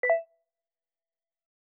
button.mp3